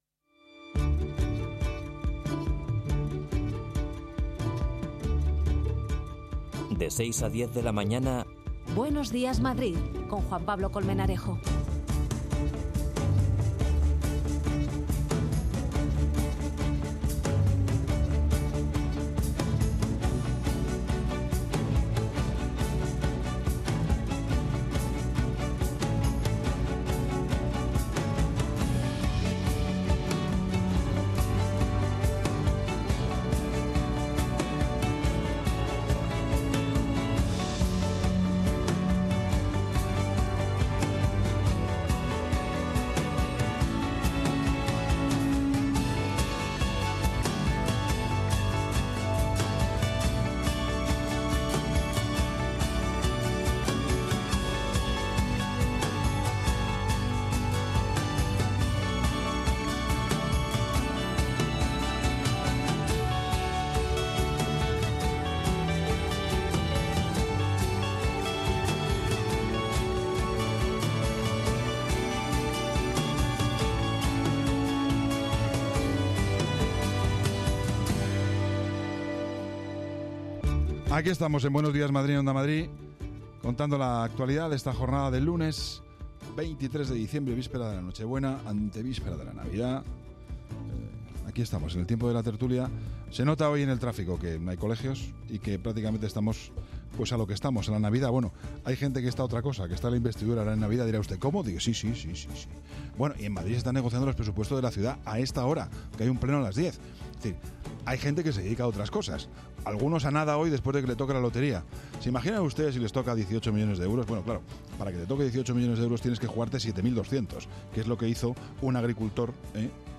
Entrevista a Francesc de Carreras, Fundador de Ciudadanos y Miembros de la Academia de Ciencias Morales y Políticas.